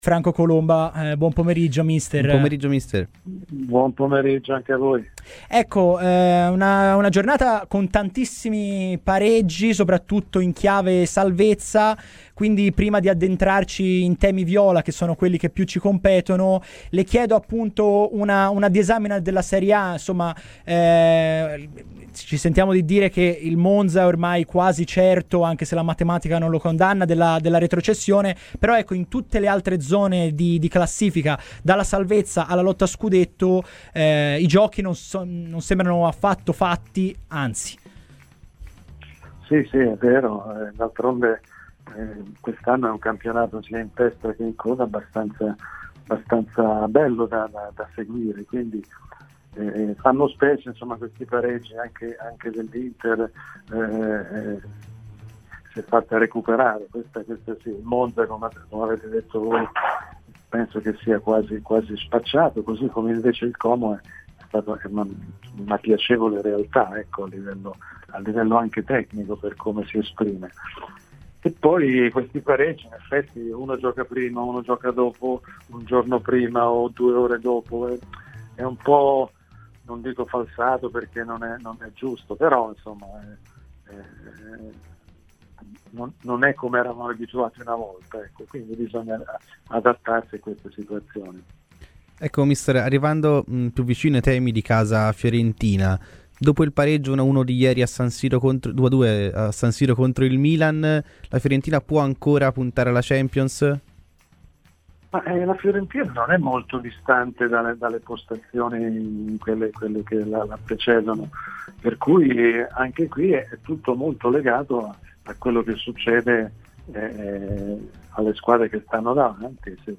Franco Colomba, allenatore di lungo corso che in carriera tra le altre ha allenato anche Verona e Cagliari, è intervenuto ai microfoni di Radio FirenzeViola, durante la trasmissione "Viola Weekend" per analizzare il pareggio di ieri tra la Fiorentina e il Milan a San Siro.